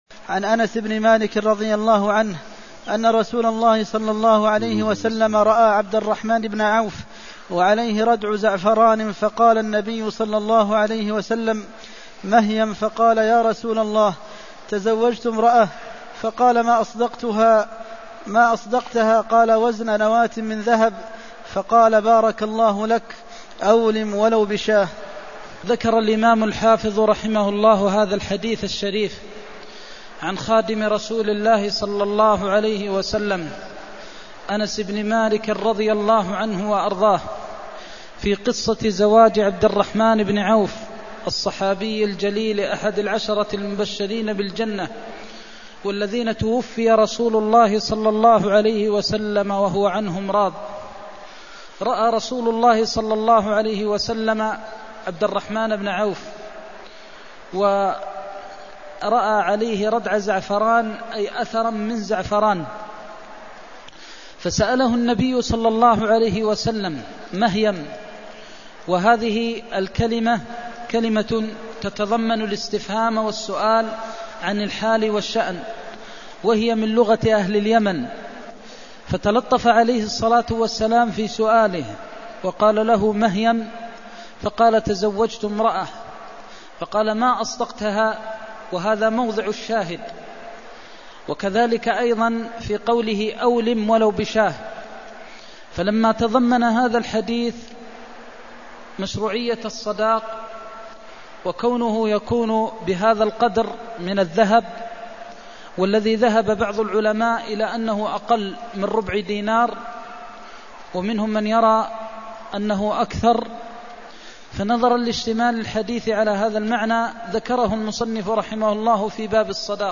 المكان: المسجد النبوي الشيخ: فضيلة الشيخ د. محمد بن محمد المختار فضيلة الشيخ د. محمد بن محمد المختار بارك الله لك أولم ولو بشاة (299)